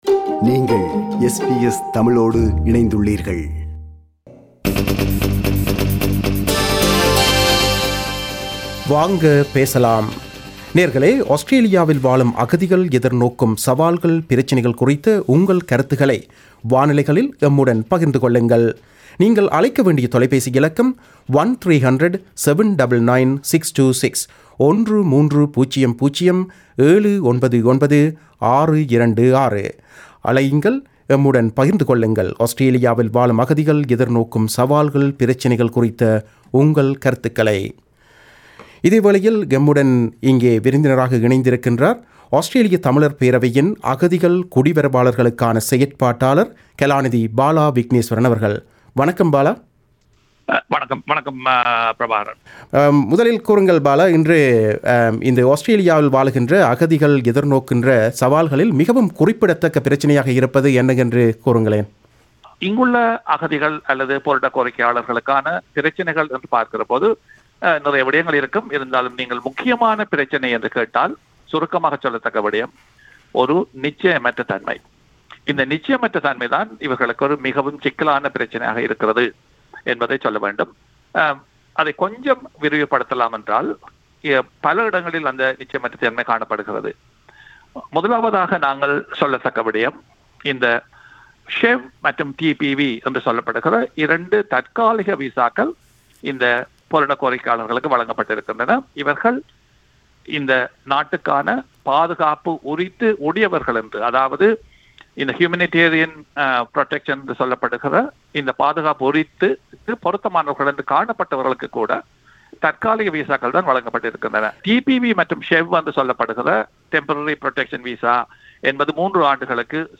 Talkback on Refugees.